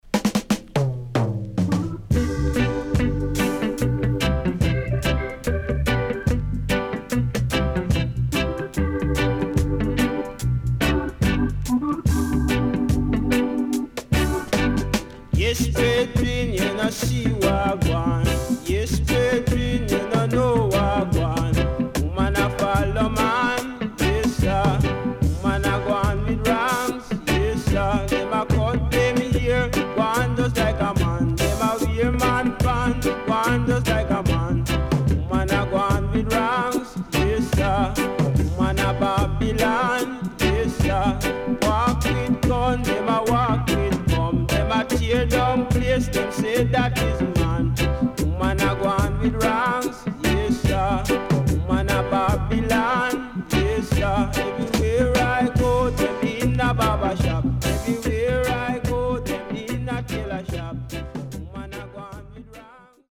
5アーティスト全13曲入りのルーツコンピレーションアルバム.各アーティストのいなたい感じが何とも心地の良い作品です
SIDE A:少しチリノイズ入りますが良好です。
SIDE B:少しプレスノイズ入る曲あります。